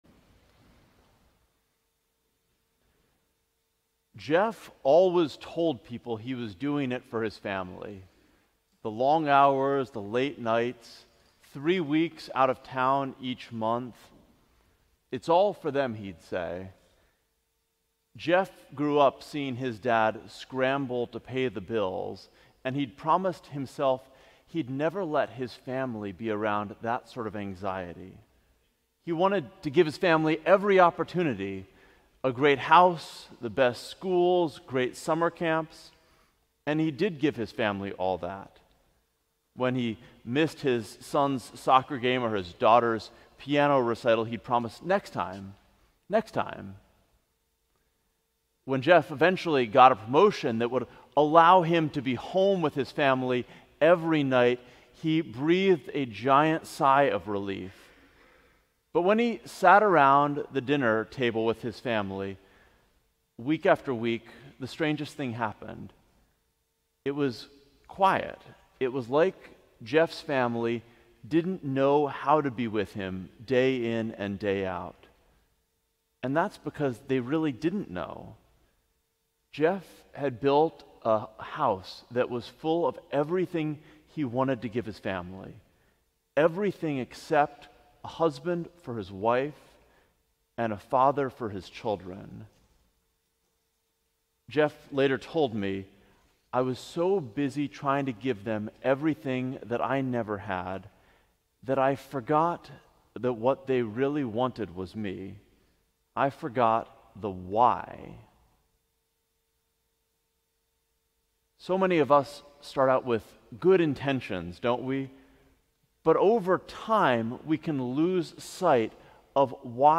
Sermon: Remembering Why - St. John's Cathedral